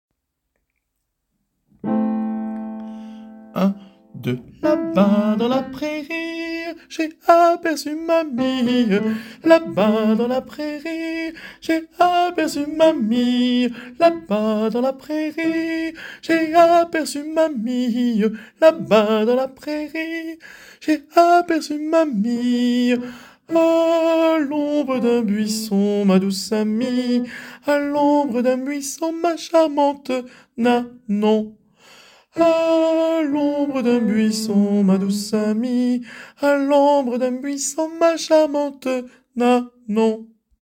Téléchargez la voix des sopranos
la-bas-dans-la-prairie-soprano-Ece82CZywe3MLtCm.mp3